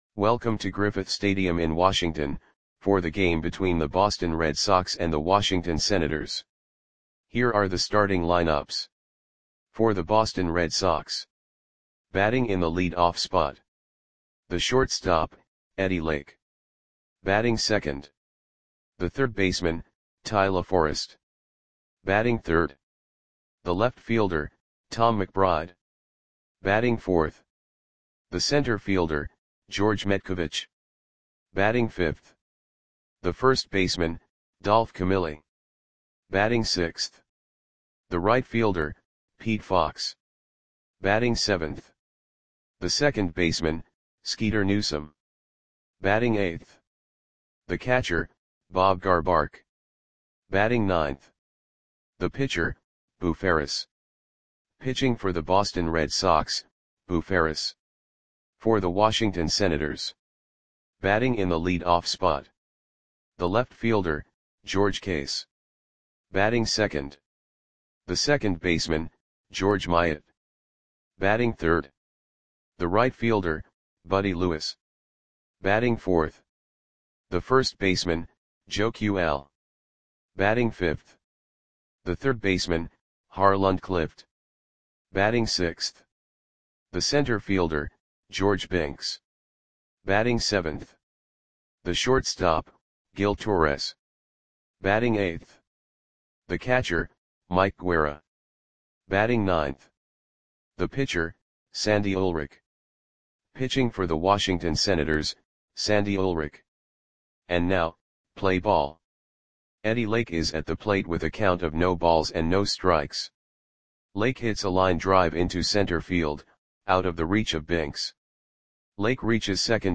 Audio Play-by-Play for Washington Senators on August 4, 1945
Click the button below to listen to the audio play-by-play.